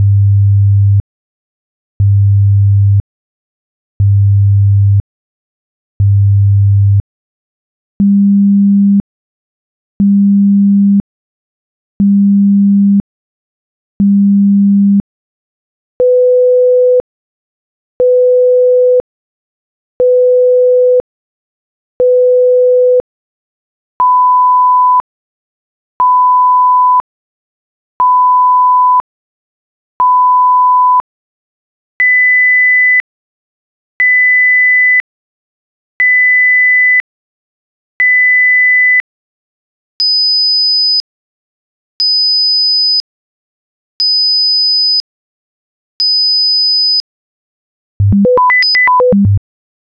Atlas - STest1-Pitch-100,200,500,1000,2000,5000.wav